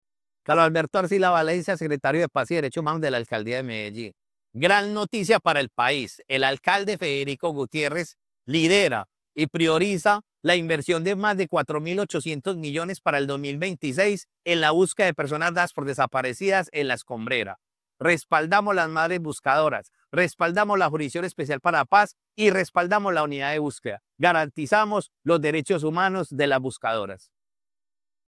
Declaraciones del secretario de Paz y Derechos Humanos, Caros Alberto Arcila Valencia.
Declaraciones-del-secretario-de-Paz-y-Derechos-Humanos-Caros-Alberto-Arcila-Valencia..mp3